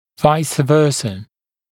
[ˌvaɪsə’vɜːsə][ˌвайсэ’вё:сэ]наоборот